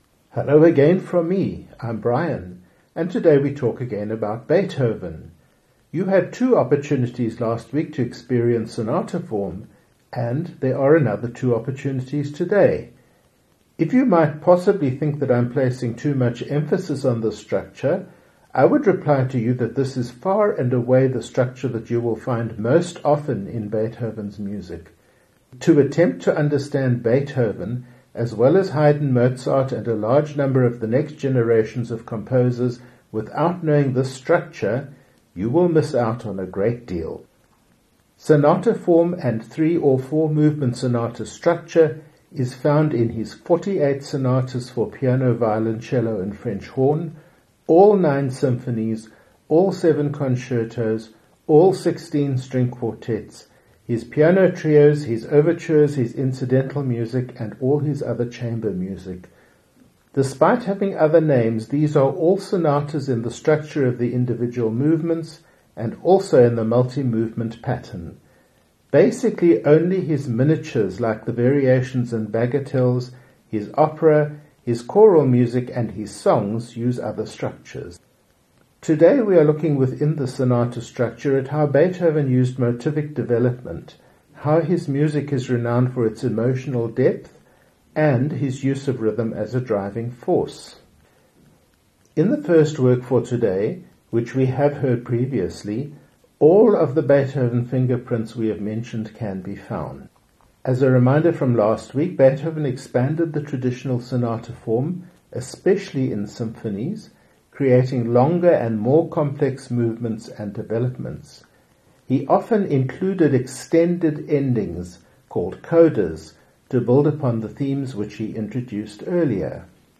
We start with the famous Symphony 5 with the “fate” motif, and in this symphony, we will find examples of the features I call Beethoven’s fingerprints. After that a much easier work, a Wind Sextet, not often heard in concert.
Ludwig van Beethoven_ Symphony No. 5 in C Minor, Op. 67 Performed by the Berliner Philharmoniker conducted by Herbert von Karajan – · Ludwig van Beethoven_ Sy